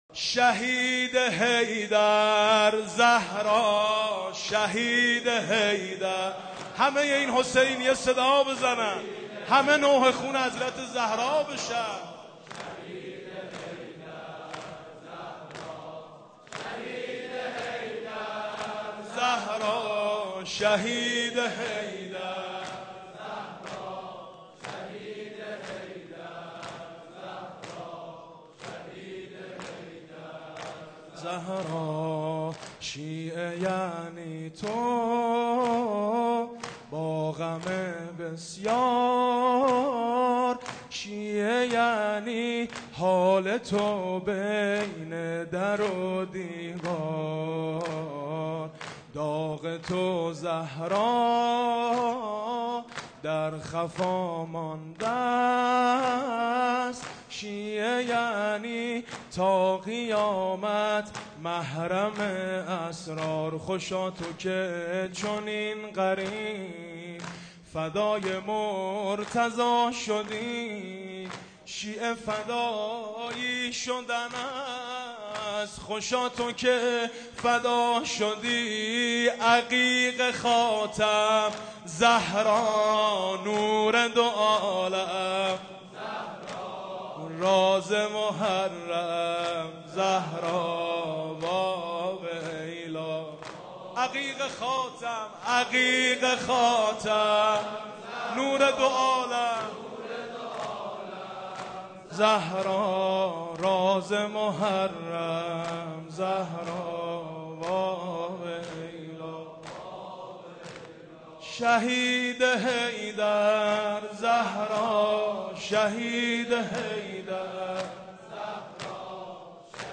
زمینه | شیعه یعنی تو با غم بسیار، شیعه یعنی حال تو بین در و دیوار
مداحی حاج میثم مطیعی | شب شهادت حضرت فاطمه سلام الله علیها | محضر رهبر انقلاب اسلامی | حسینیه امام خمینی(ره)